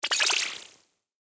interact.ogg